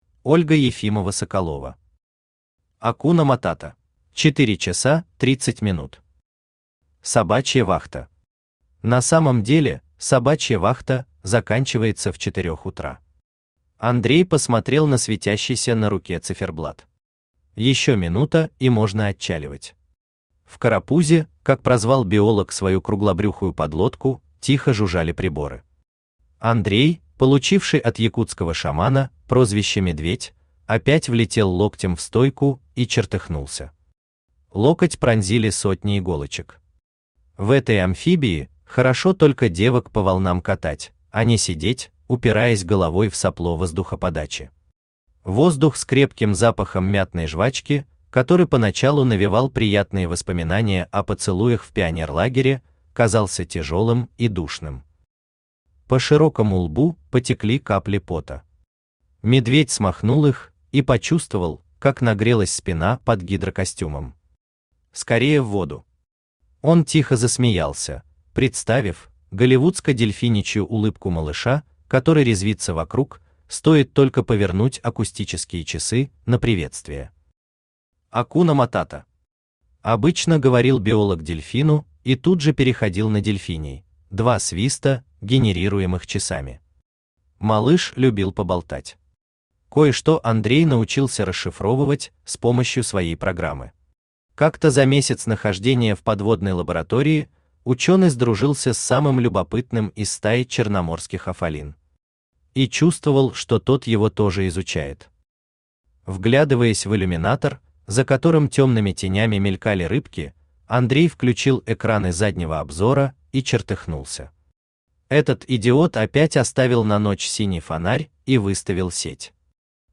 Аудиокнига Акуна матата | Библиотека аудиокниг
Aудиокнига Акуна матата Автор Ольга Ефимова-Соколова Читает аудиокнигу Авточтец ЛитРес.